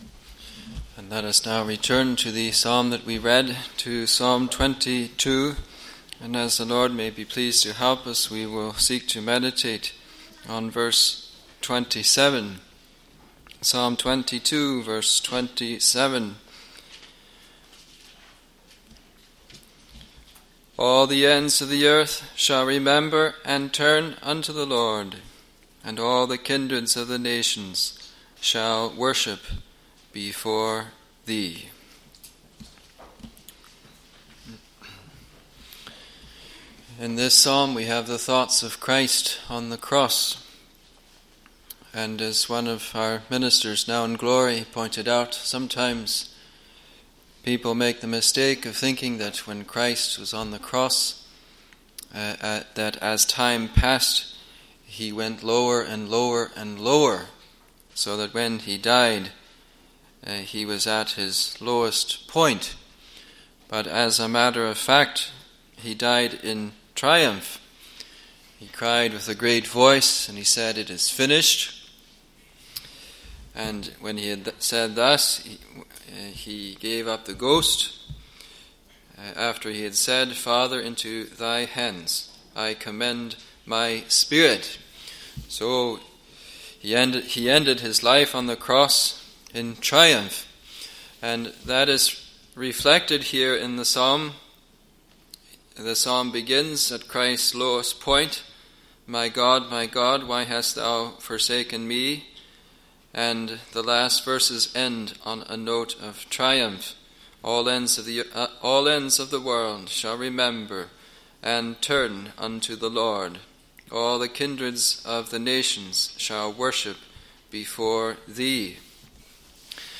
Thanksgiving Service | Free Presbyterian Church of Scotland in New Zealand